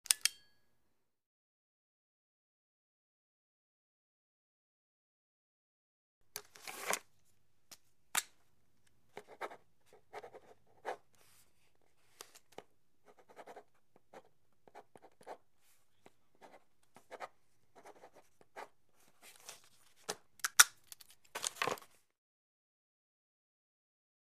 Click, Pen | Sneak On The Lot
Pen Click To Write, Light Spring